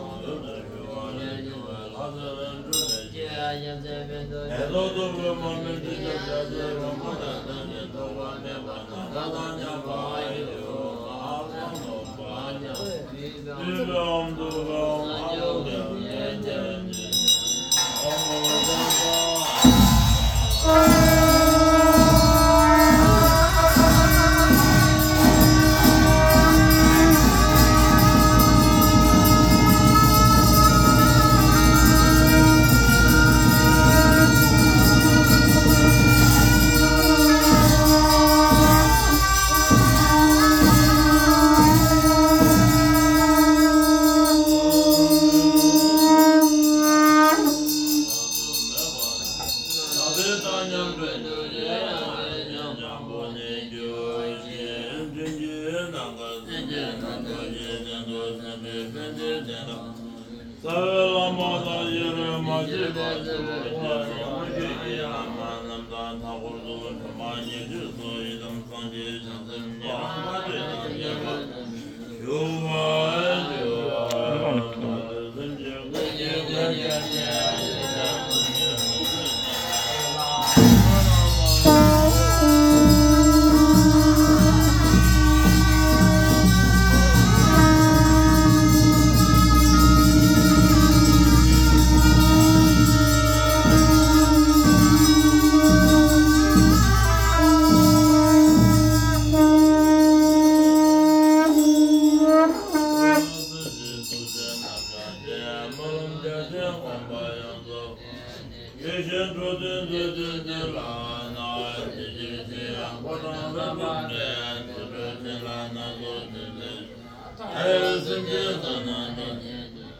Puja
3-minute Medicine Buddha puja audio recording at Pokhara's monastery